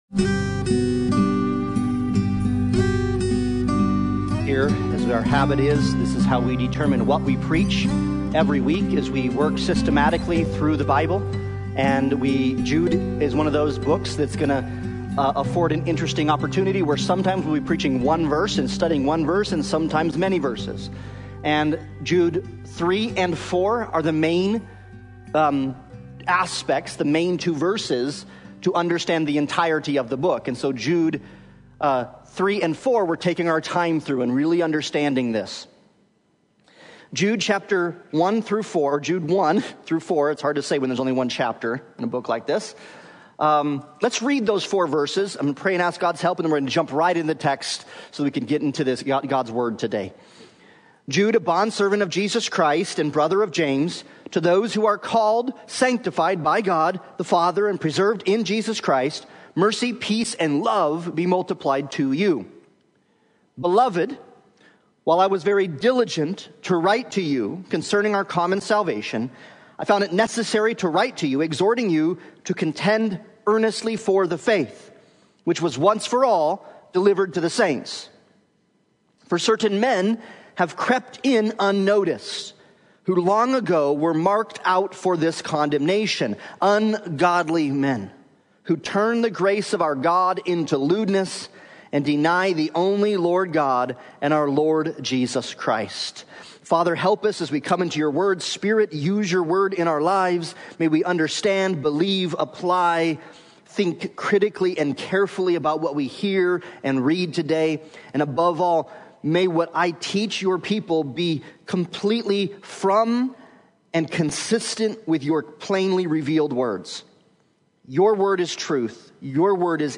Jude 1-4 Service Type: Sunday Morning Worship « Contend For The Faith